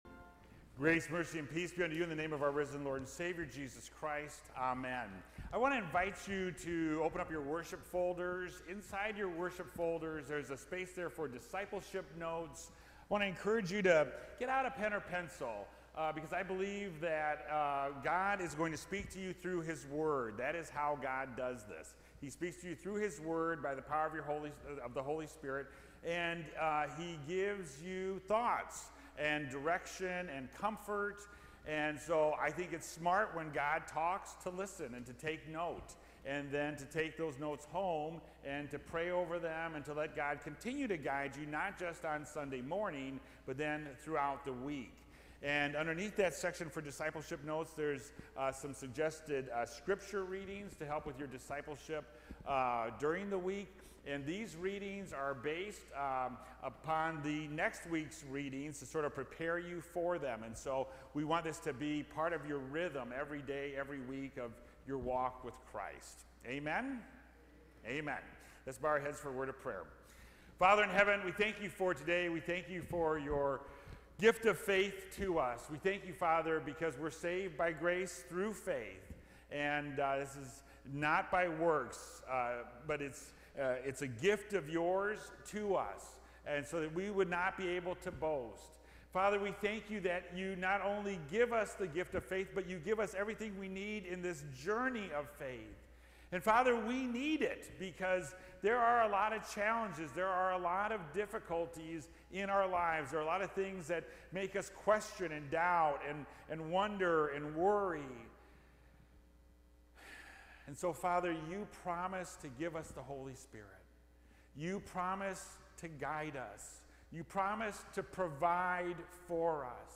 Faith Lutheran Church | Church in Lake Forest, IL
Sermon